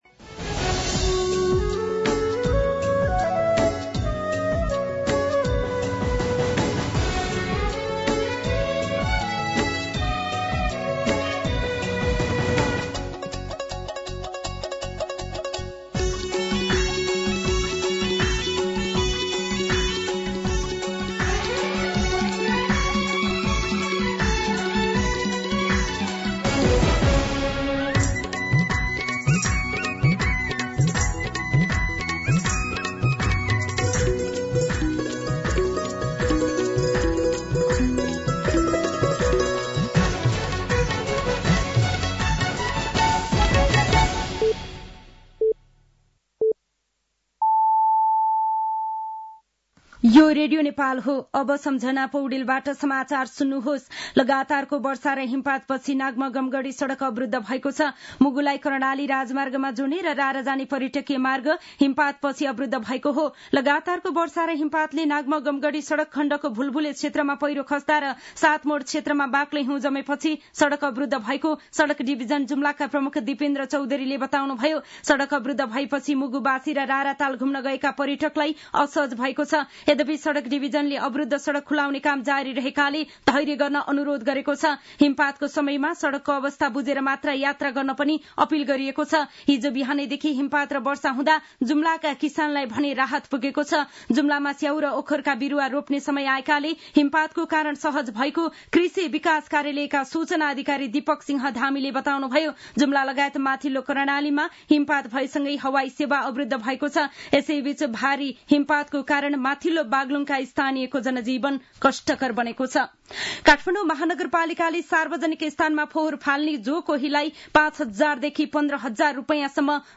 दिउँसो ४ बजेको नेपाली समाचार : १८ फागुन , २०८१